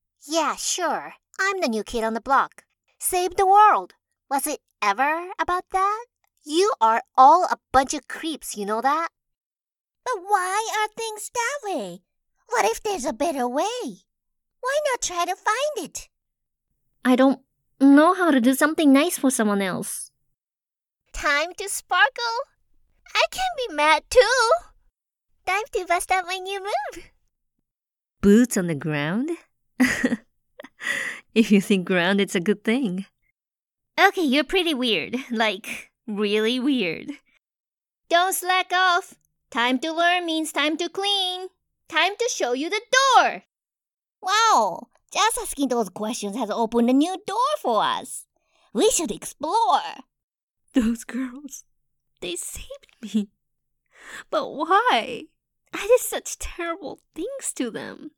Foreign Language Voice Samples
Video Games
I offer a wide variety of voices! From animated/high-pitched for anime – animation – video games – cartoons to professional newscaster, take your pick!
Microphone: Audio-Technica